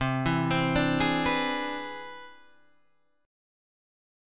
C+M9 chord